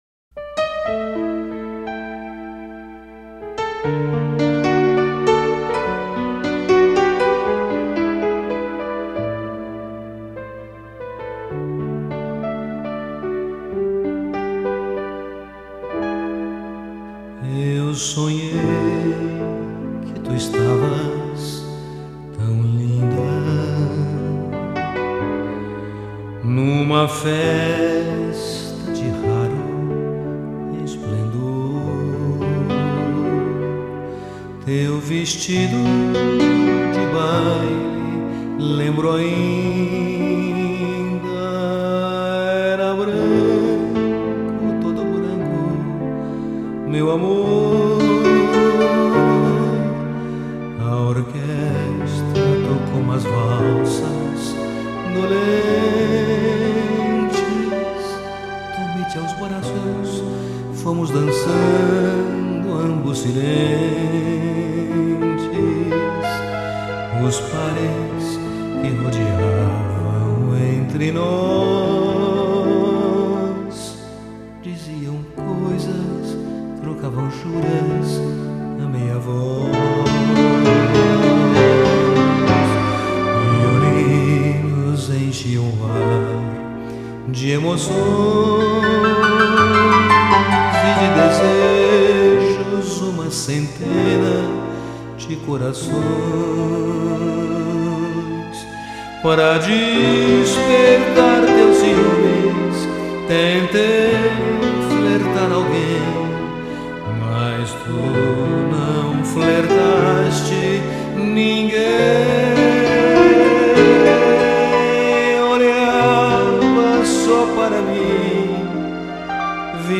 este era o título do seu CD com músicas românticas